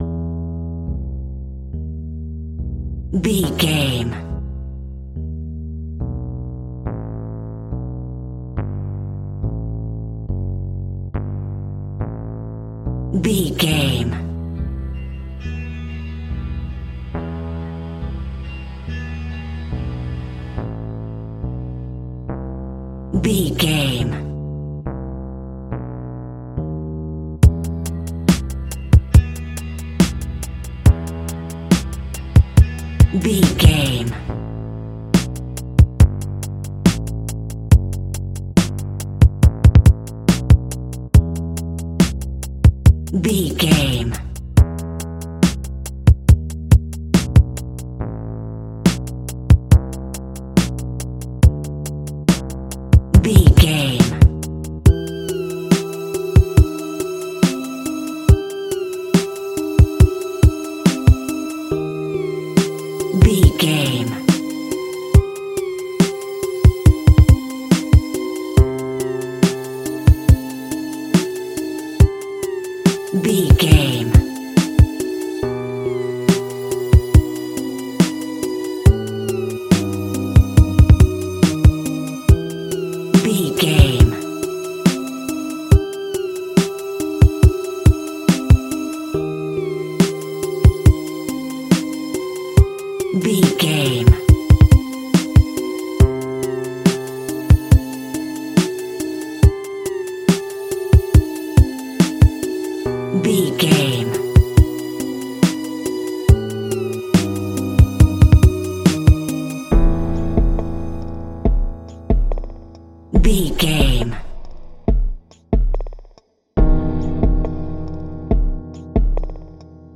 Aeolian/Minor
Slow
scary
ominous
dark
eerie
piano
synthesiser
strings
drums
Synth Pads
atmospheres